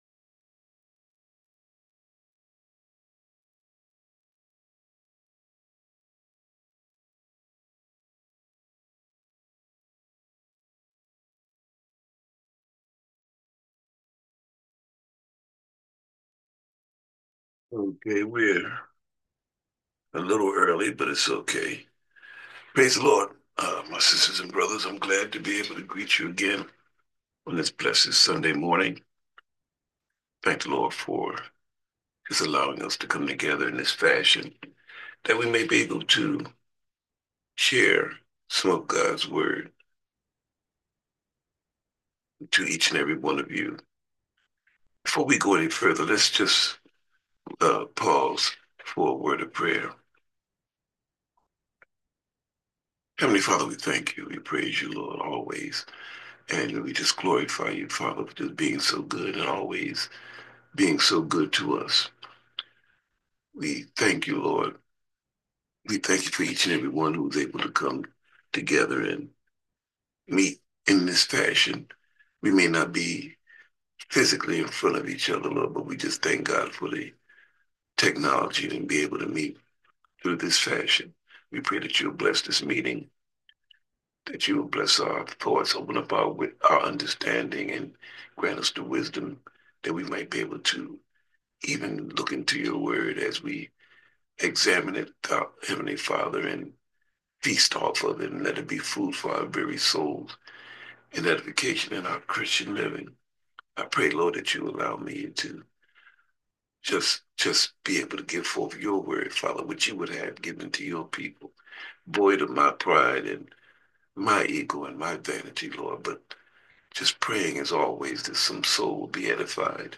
who-got-the-power_sermon_audio_1.m4a